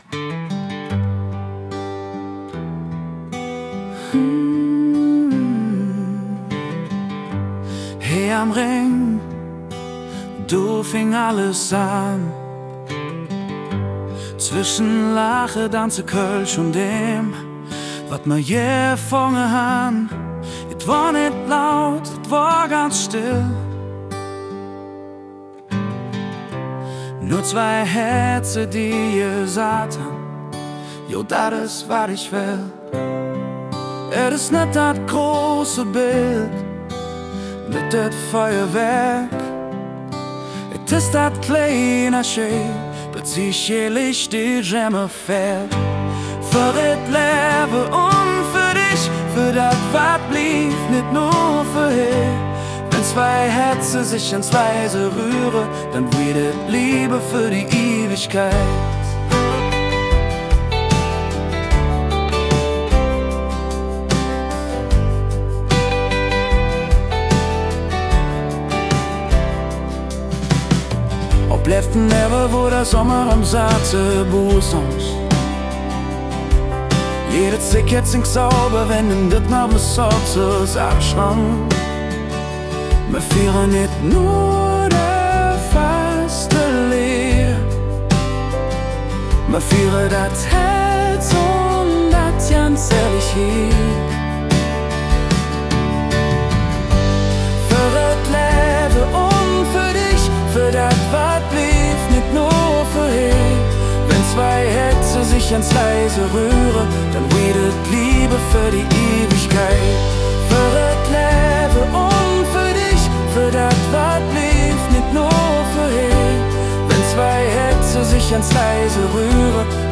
Musik & Produktion: Suno AI